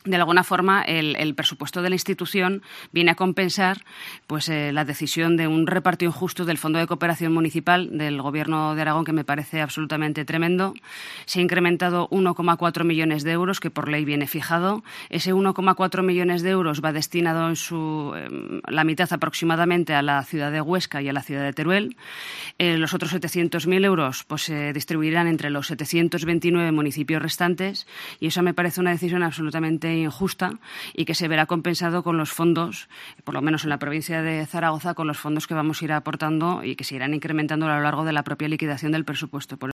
La vicepresidenta de la DPZ, Teresa Ladrero, valora el presupuesto de la institución para el 2024